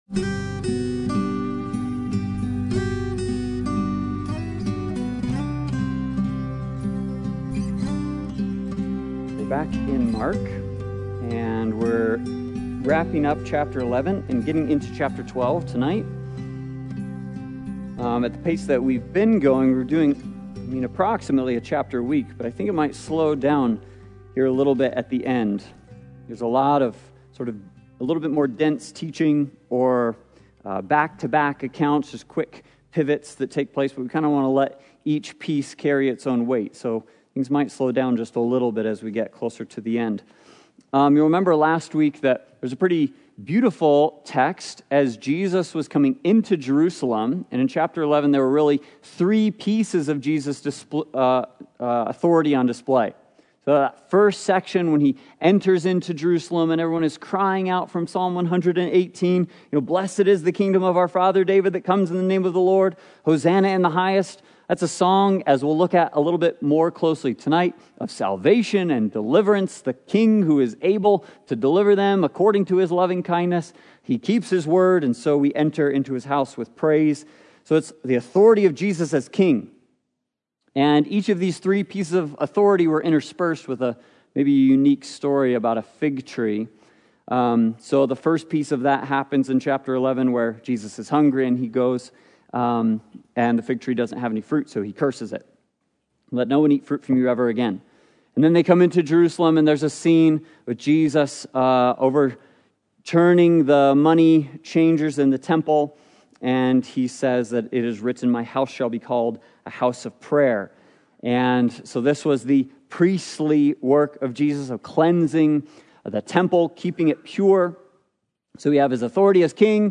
Passage: Mark 11-12 Service Type: Sunday Bible Study « Wisdom & Creation